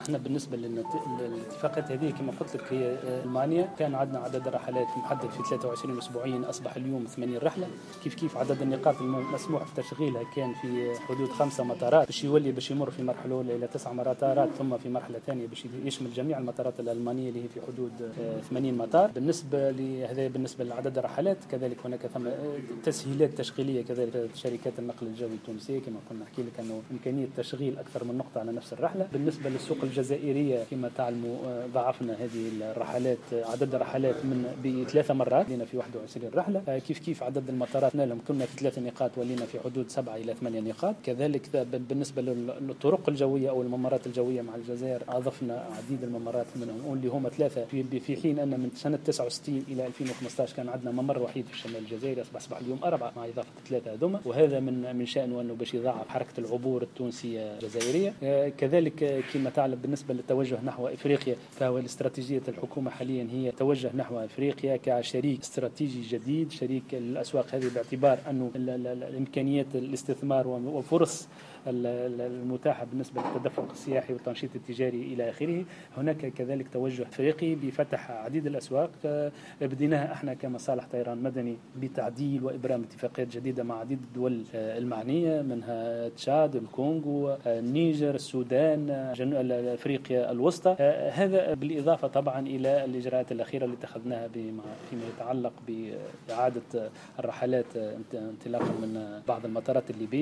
خلال ندوة صحفية نظمت اليوم لتقديم كل التفاصيل حول الإتفاقيات الدولية في مجال الطيران المدني مع عدد من شركات الطيران الأوروبية والعربية والإفريقية